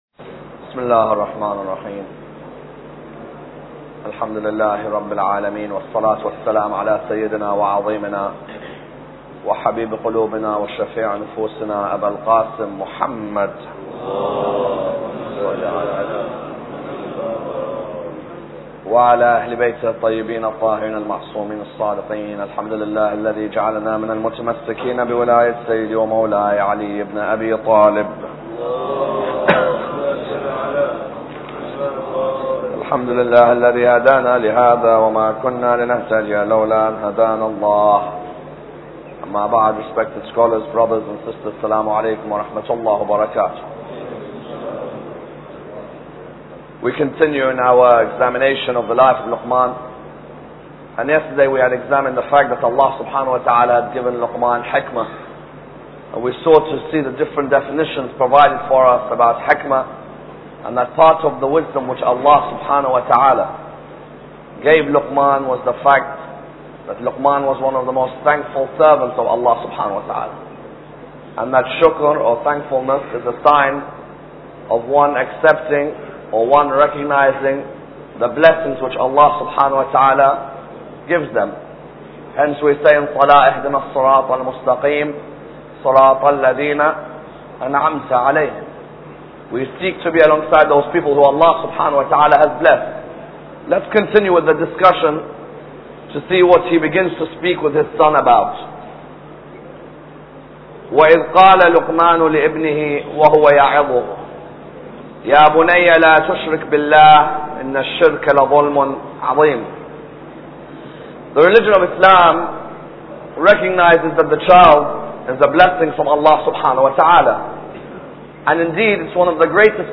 Lecture 7